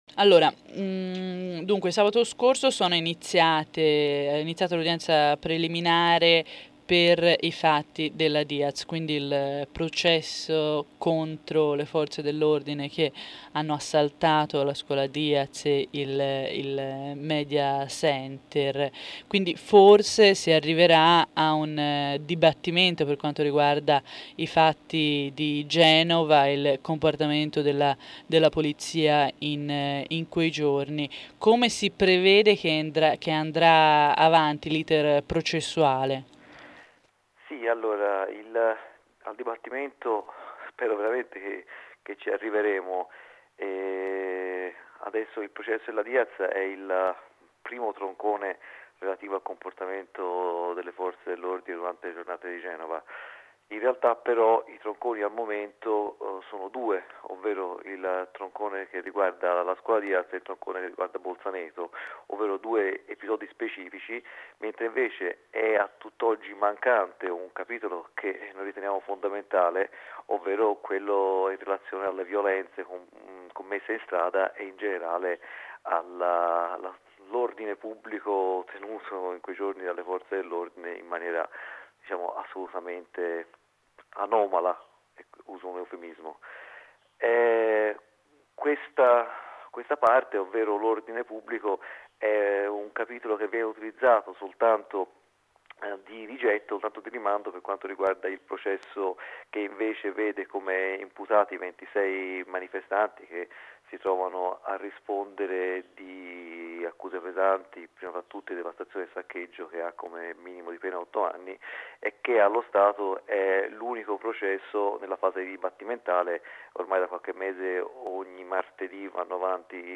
[Genova G8] Non dimenticare Genova - aiuto al GLF - intervista di Novaradio